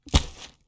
Close Combat Attack Sound 6.wav